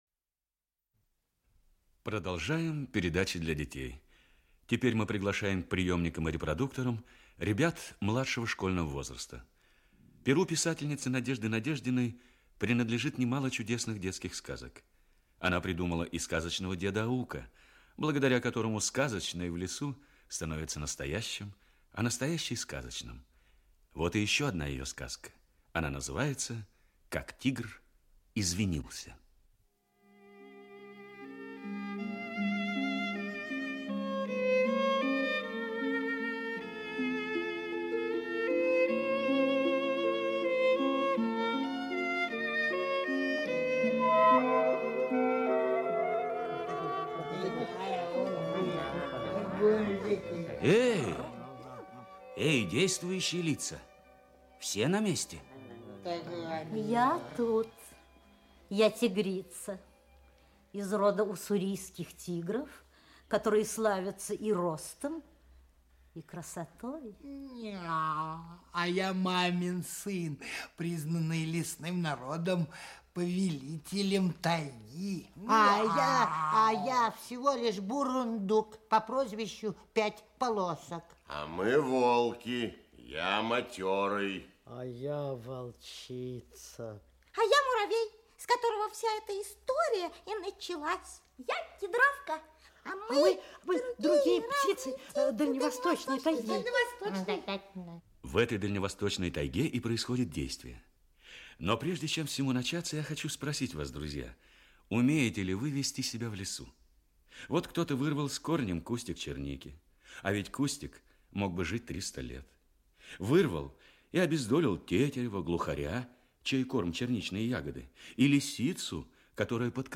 Аудиокнига Как тигр извинился | Библиотека аудиокниг
Aудиокнига Как тигр извинился Автор Надежда Надеждина Читает аудиокнигу Актерский коллектив.